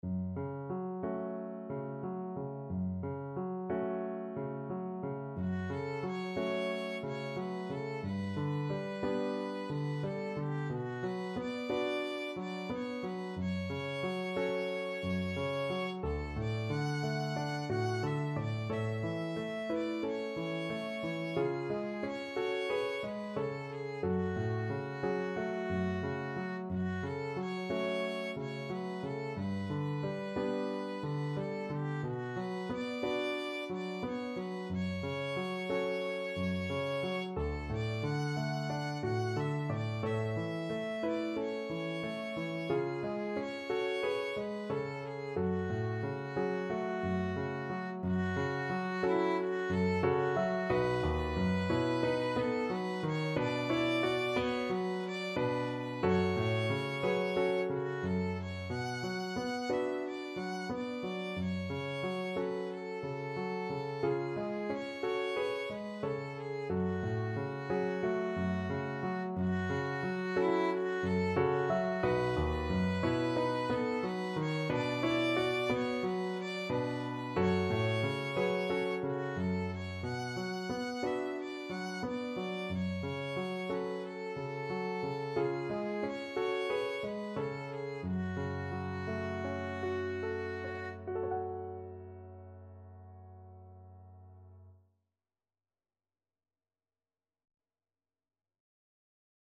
Gently Flowing =c.90
4/4 (View more 4/4 Music)
Classical (View more Classical Violin Music)